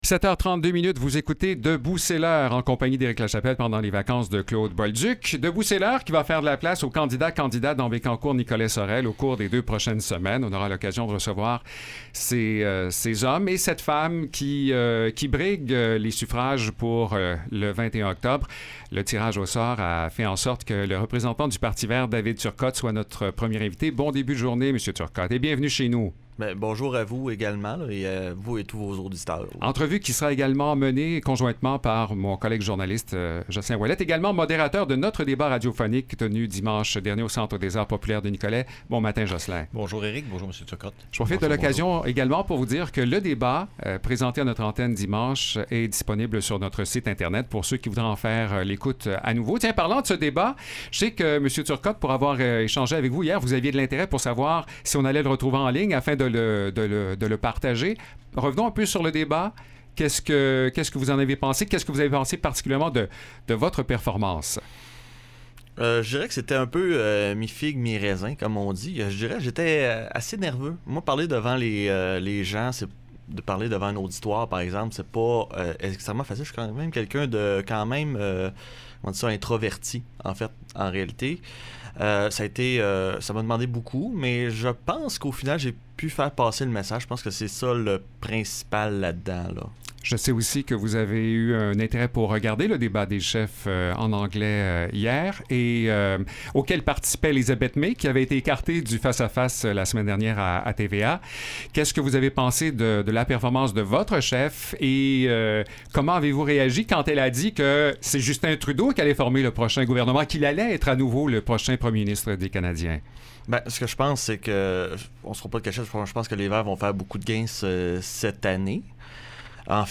VIA 90,5fm vous donne l’occasion de connaitre les candidats qui se présentent dans la circonscription de Bécancour-Nicolet-Saurel. Lors d’une première entrevue